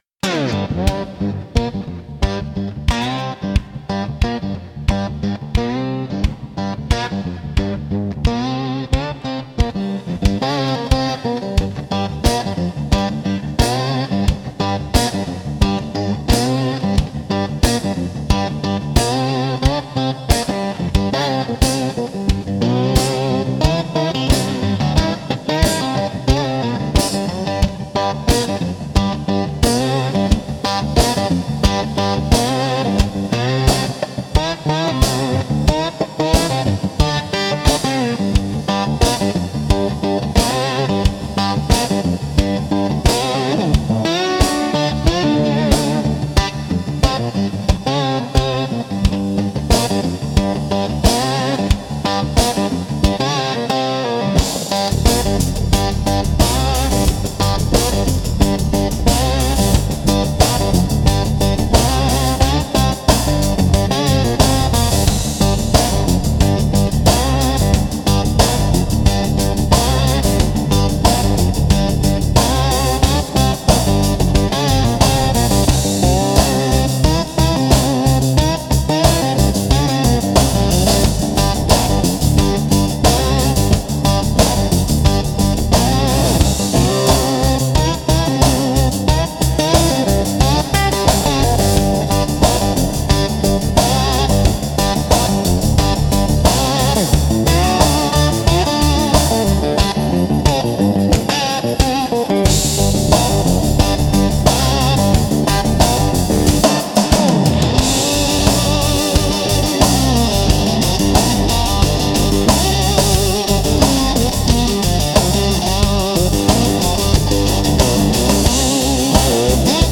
Instrumental - Snakebite Groove 3.53